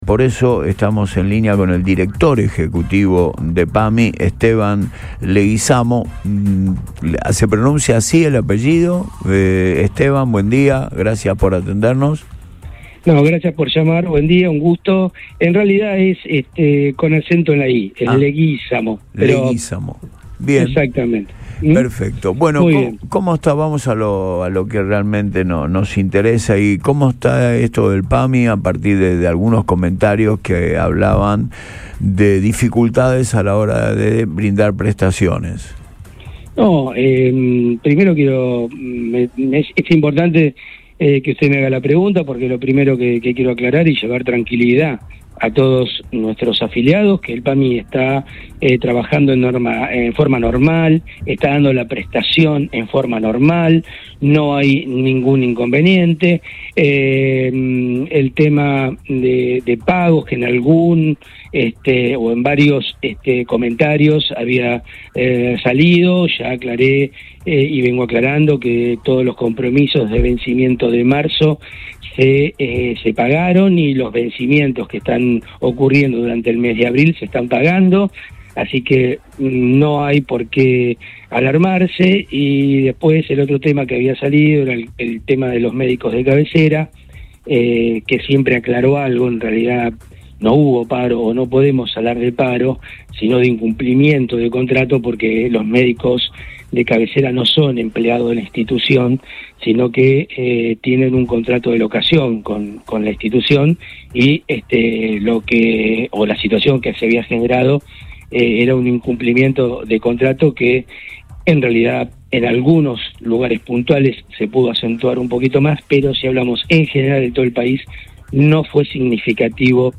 En diálogo con el programa Antes de Todo por Radio Boing 97.9, el funcionario fue contundente: “El PAMI está trabajando en forma normal, está dando la prestación en forma normal, no hay ningún inconveniente”.